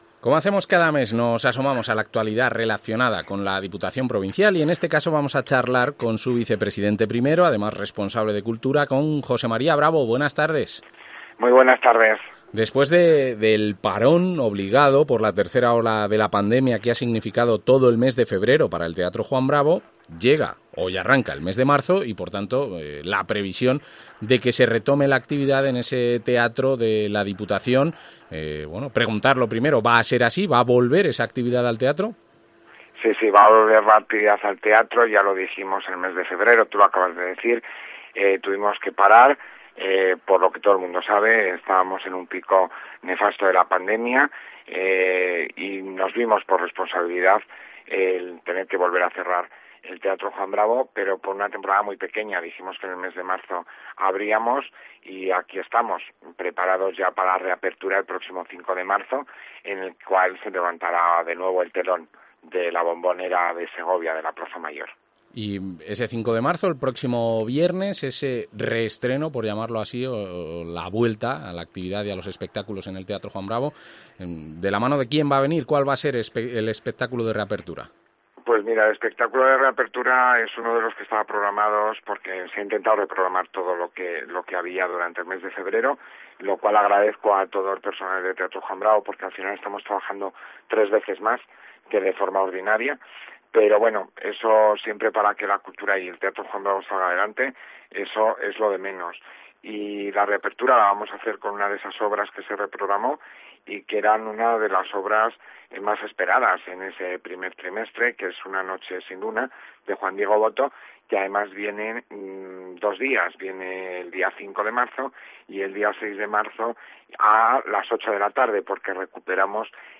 Entrevista al vicepresidente primero de la Diputación de Segovia, José María Bravo